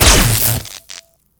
雷电.wav